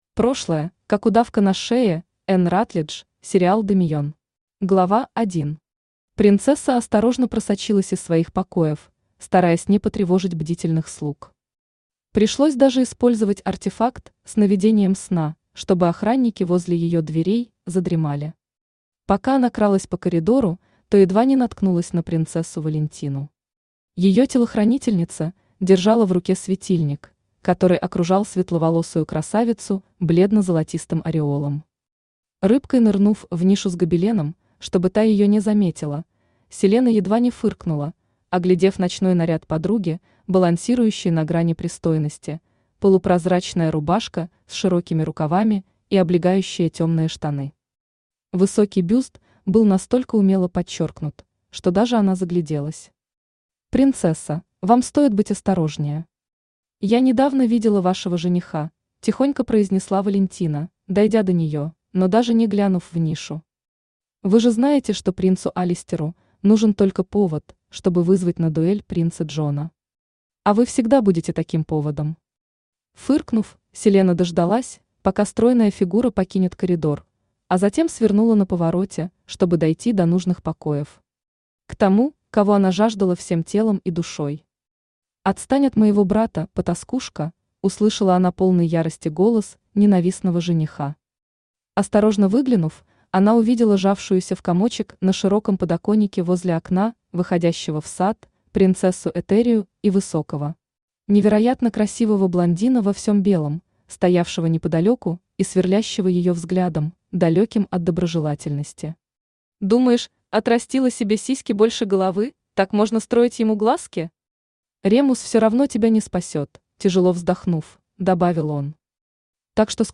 Аудиокнига Ледяная принцесса | Библиотека аудиокниг
Aудиокнига Ледяная принцесса Автор Кристина Воронова Читает аудиокнигу Авточтец ЛитРес.